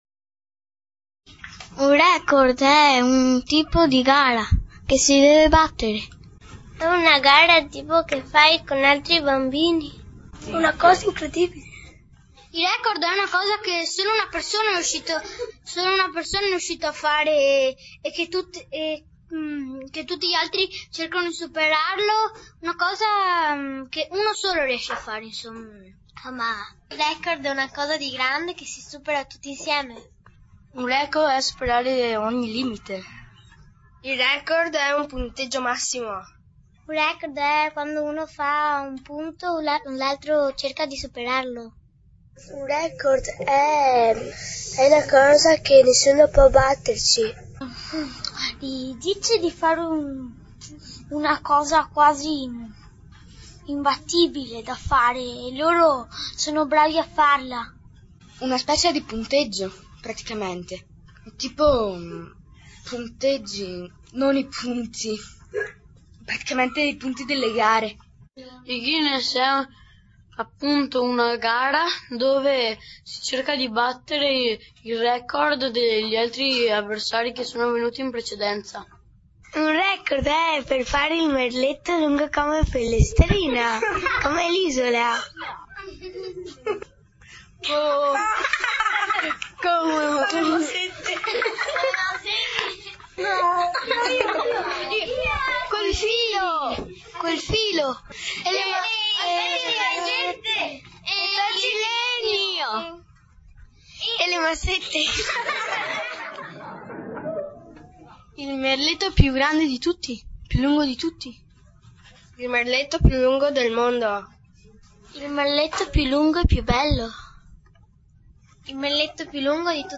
scarica una presentazione dell'attività didattica in formato MP3 (1.61 Mb)